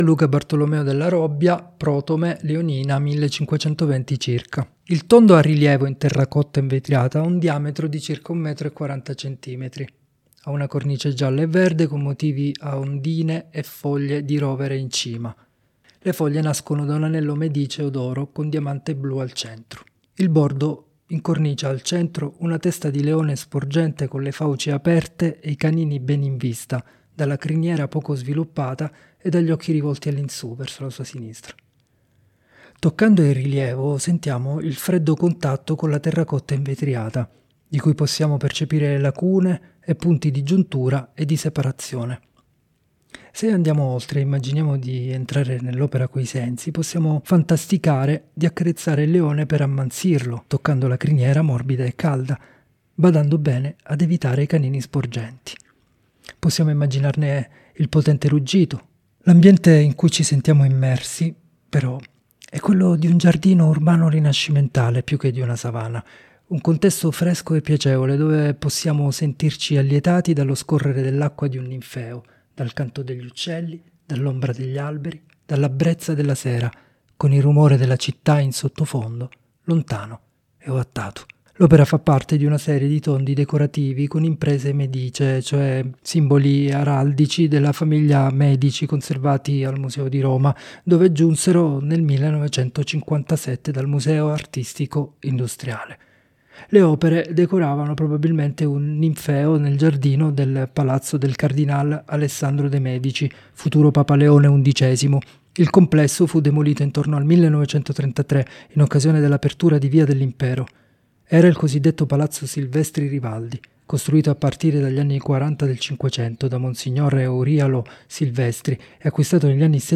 Audiodescrizioni sensoriali opere selezionate: